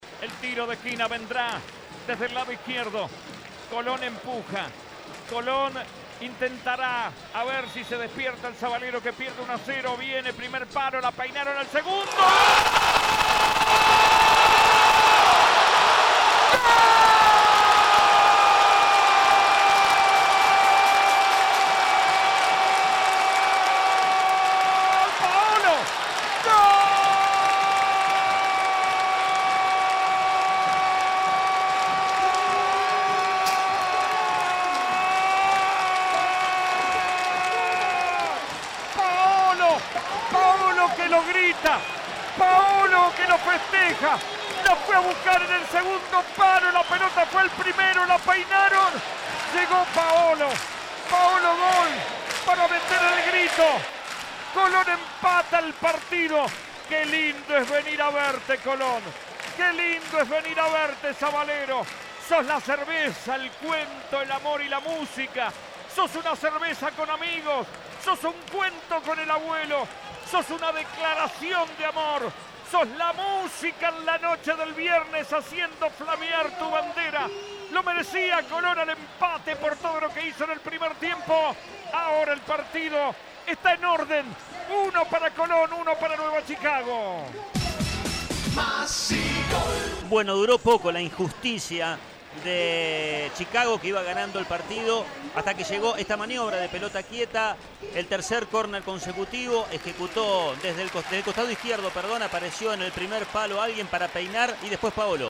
Gol Paolo Goltz